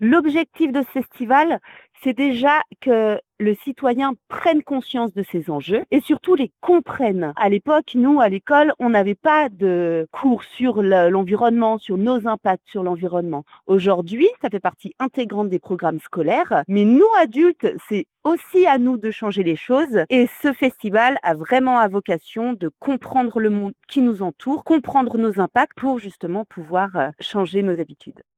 Les détails avec Isabelle Rossat-Mignod, maire adjointe de Saint-Julien-en-Genevois.